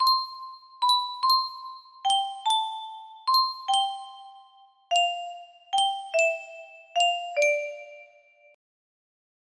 Veralos music box melody